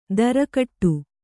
♪ dara kaṭṭu